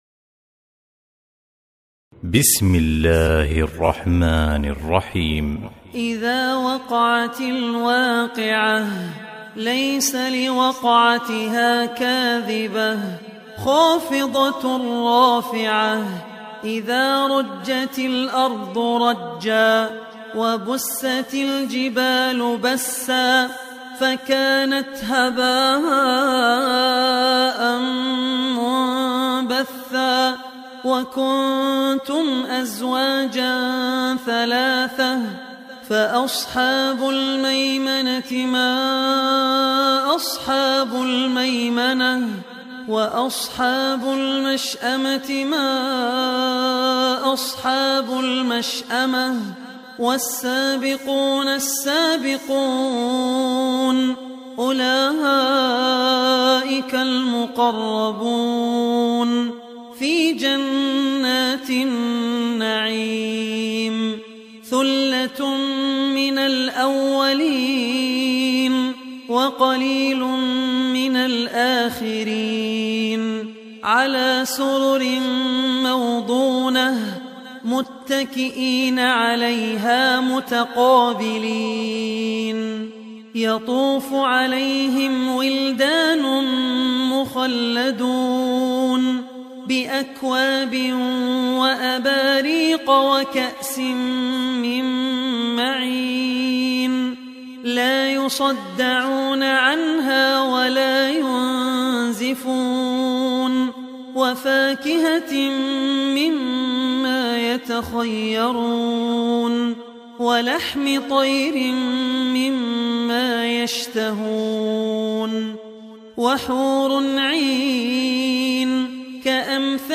Surah Al Waqiah Beautiful Recitation MP3 Download By Abdul Rahman Al Ossi in best audio quality.